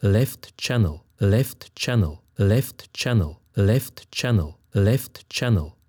Audio test: Localizzazione spaziale del suono
sinistra